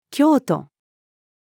京都-female.mp3